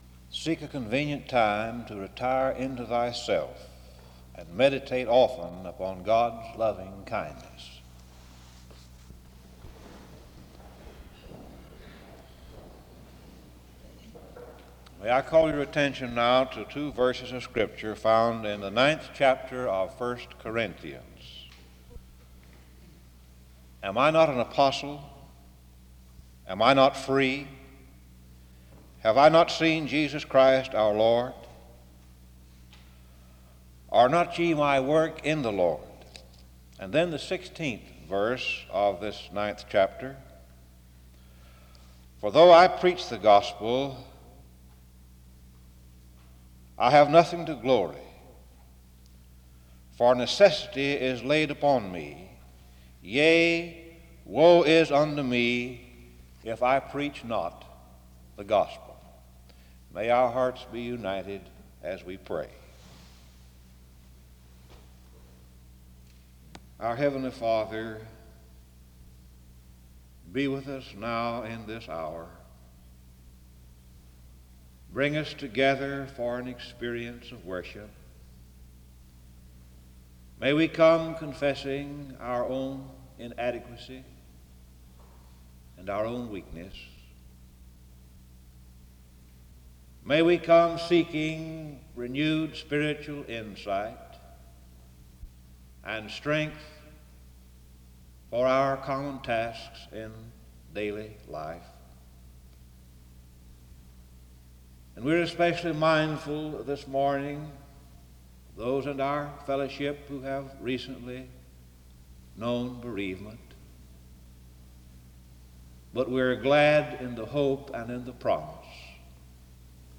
A hymn is played from 18:33-19:19.
In Collection: SEBTS Chapel and Special Event Recordings SEBTS Chapel and Special Event Recordings